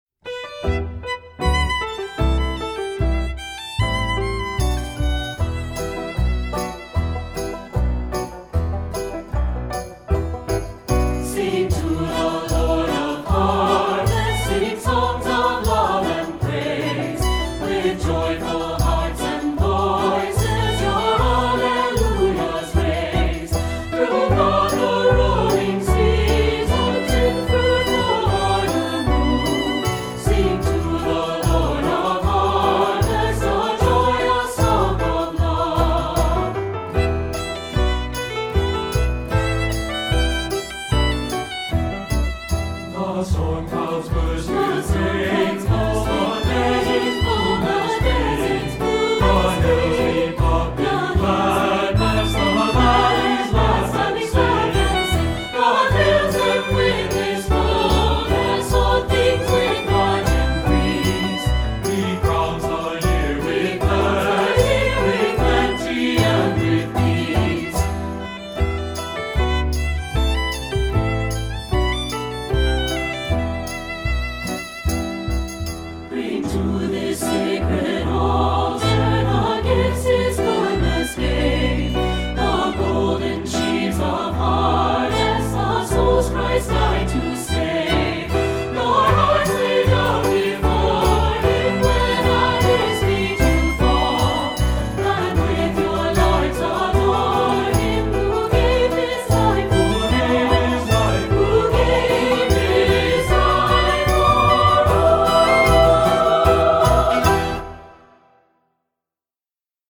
Voicing: 2-Part or SAB